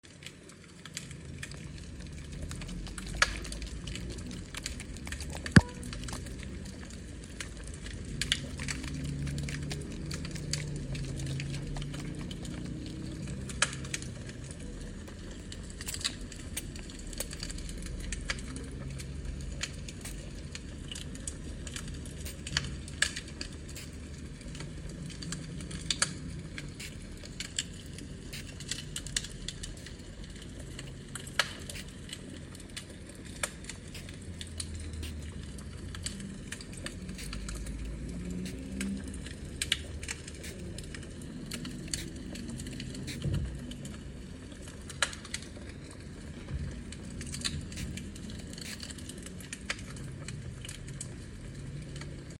ASMR | Put off the sound effects free download